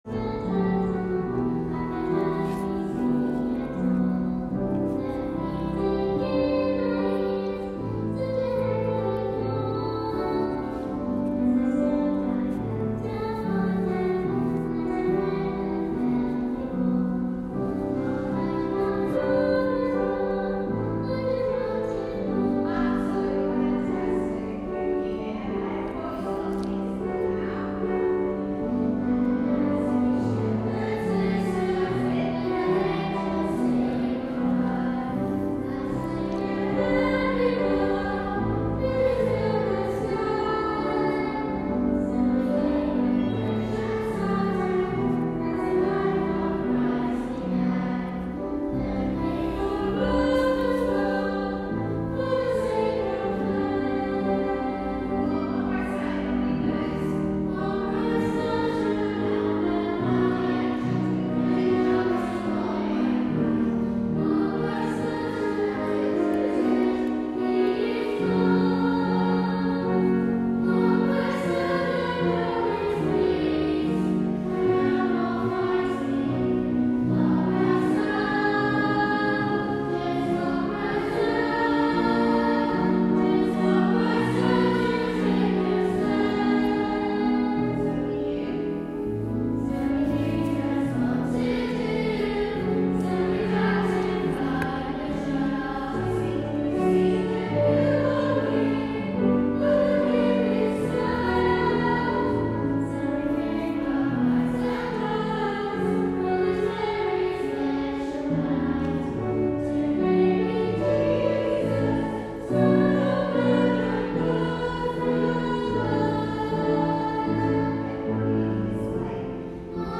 In Selby fiets ik even de stad in en wordt beloond met een prachtige 12e eeuwse kerk. Als ik binnenloop is een kinderkoortje aan het oefenen, die kleine meisjes zingen best op een hoog niveau. Ik heb een van de liederen opgenomen en zal die opname later op de website toevoegen aan het verhaal.